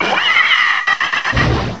cry_not_salazzle.aif